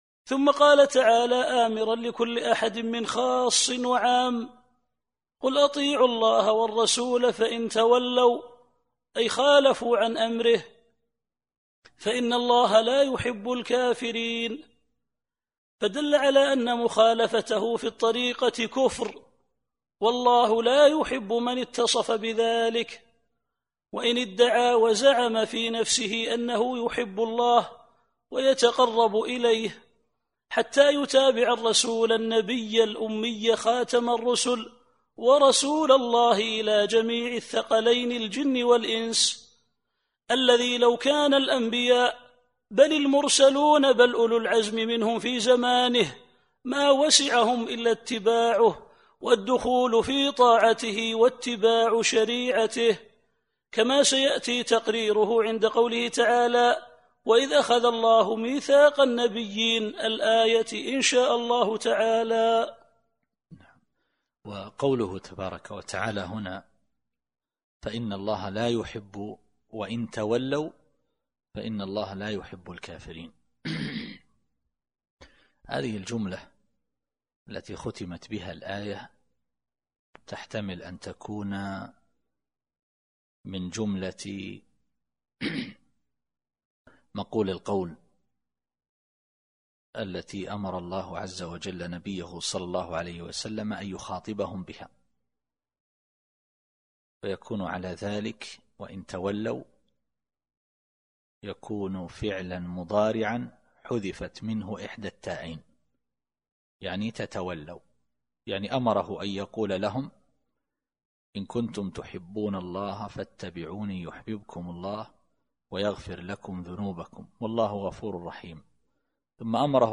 التفسير الصوتي [آل عمران / 32]